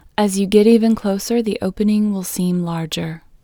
IN – the Second Way – English Female 11